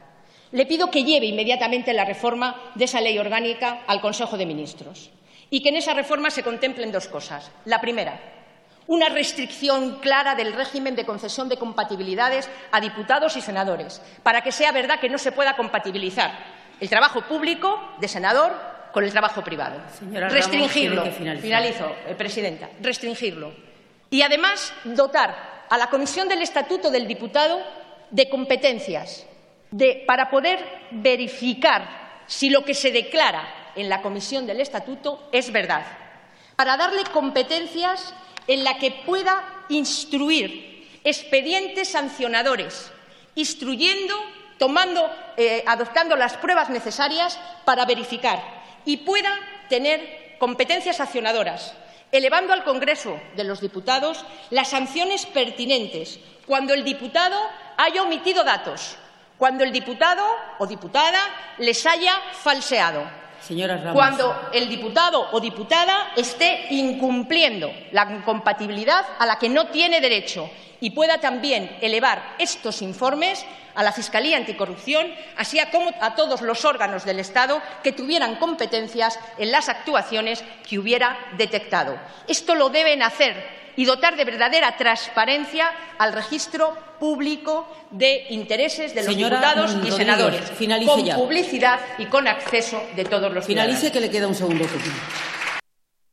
Fragmento de la intervención de Soraya Rodríguez en el Pleno. Interpelación urgente al ministro de Hacienda y AA.PP sobre su posición en materia de actuaciones para evitar la confusión y el conflicto entre intereses públicos y privados. 29/04/2015